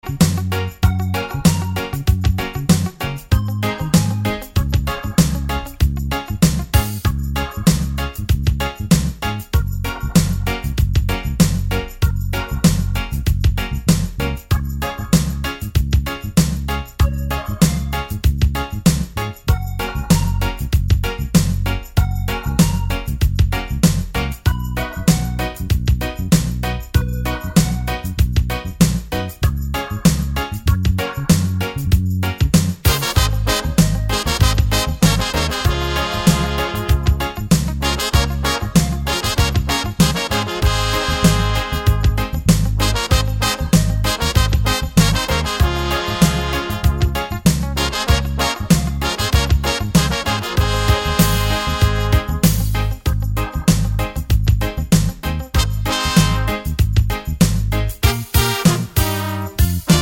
no Backing Vocals Reggae 3:24 Buy £1.50